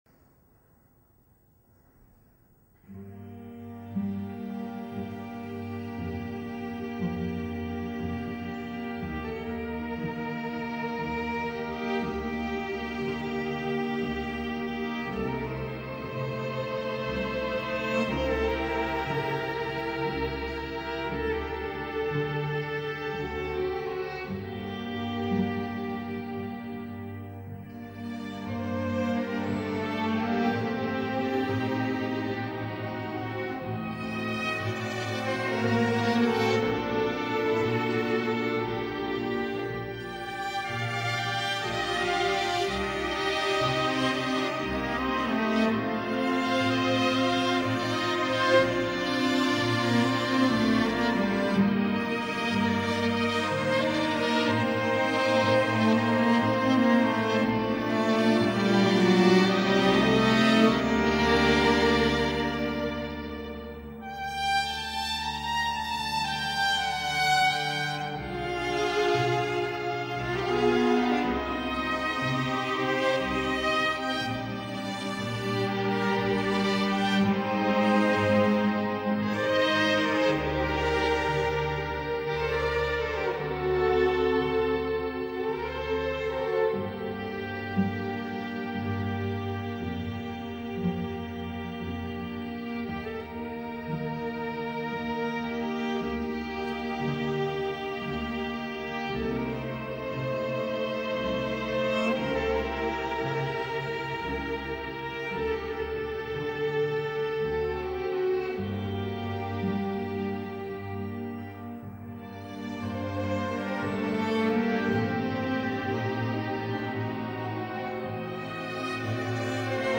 موسیقی بی کلام غمگین